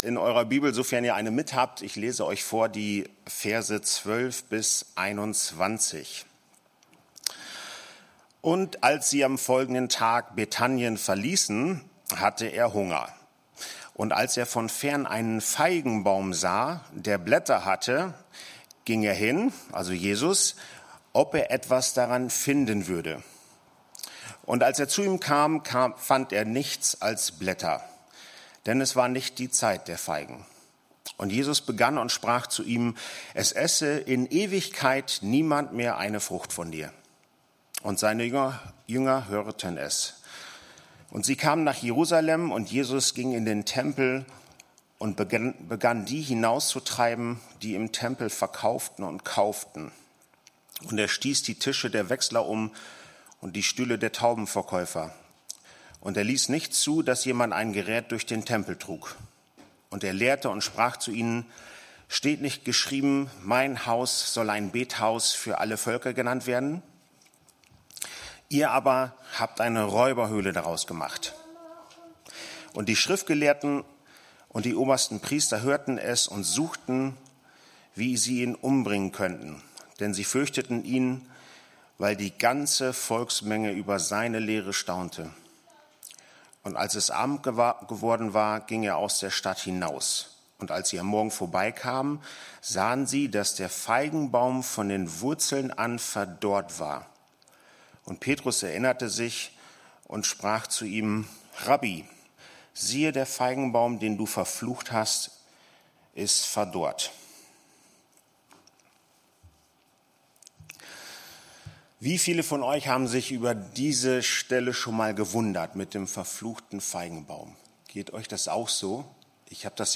Die Online-Präsenz der freien evangelischen Andreas-Gemeinde Osnabrück
PREDIGTEN